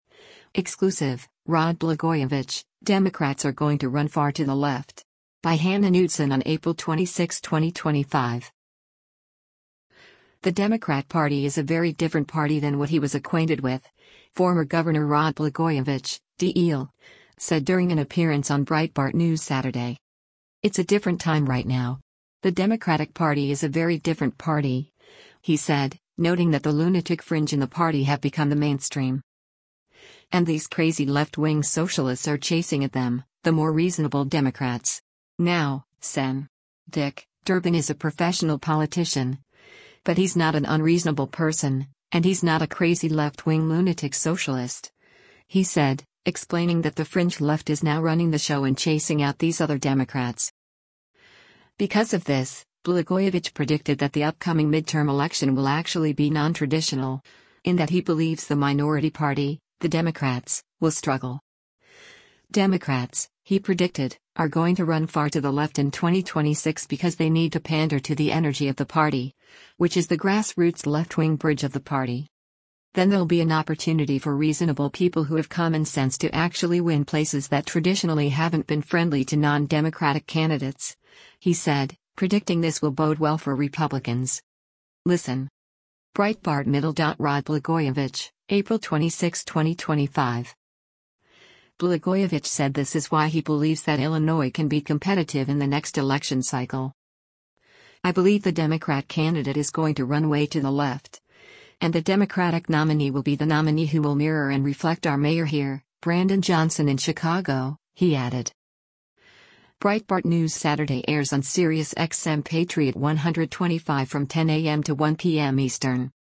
The Democrat Party is a “very different party” than what he was acquainted with, former Gov. Rod Blagojevich (D-IL) said during an appearance on Breitbart News Saturday.